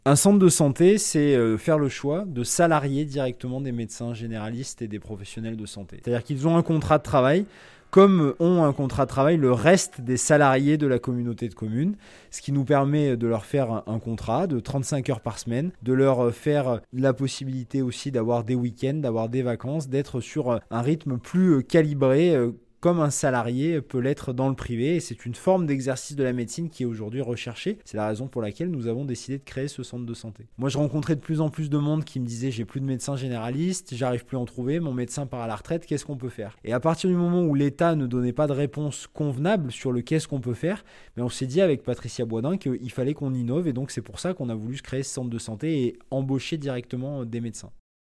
• « Un centre de santé, c’est faire le choix de salarier directement des professionnels de santé« , rappelle Pierre Jouvet, maire de Saint-Vallier et président de l’intercommunalité.